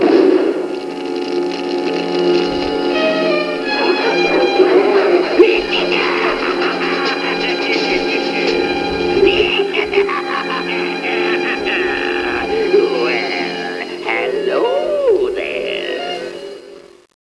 This is Auckland on a good day.